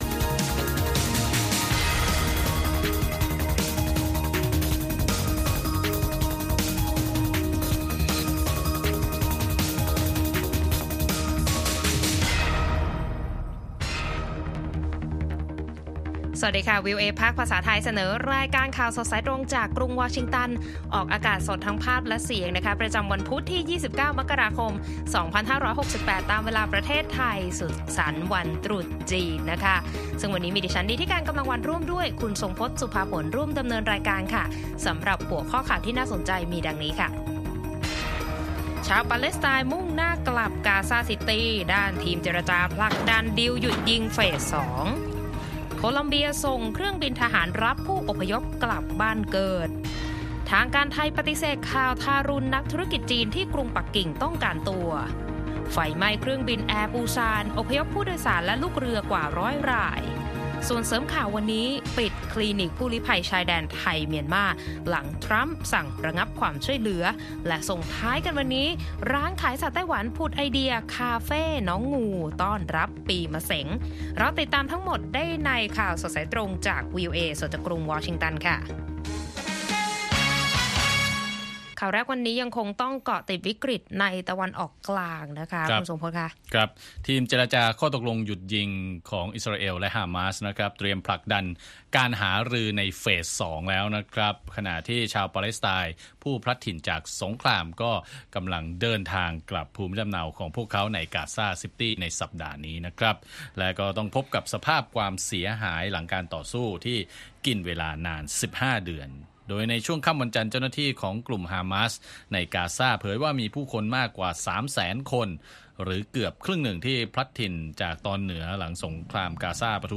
ข่าวสดสายตรงจากวีโอเอ ภาคภาษาไทย พุธ ที่ 29 มกราคม 2568